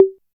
39 808 TOM.wav